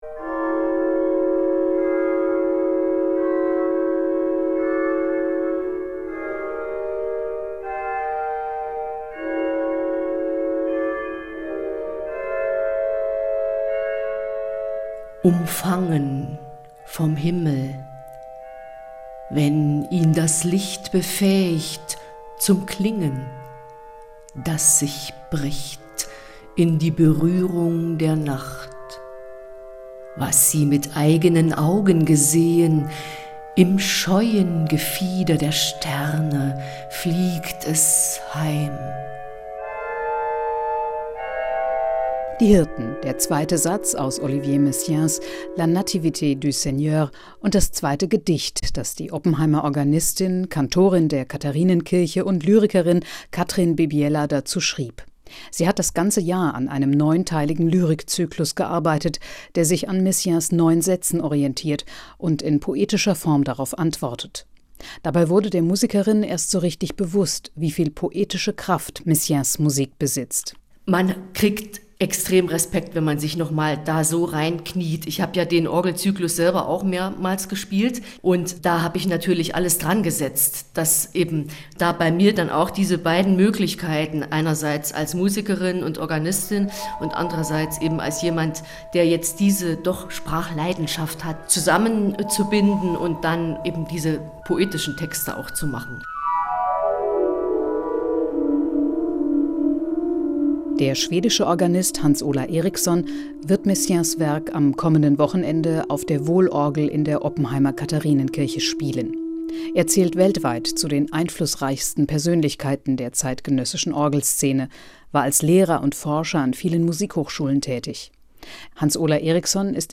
Olivier Messiaen macht in „La Nativité du Seigneur“ das Weihnachtsmysterium mit seinen schwebenden Orgelklängen hörbar.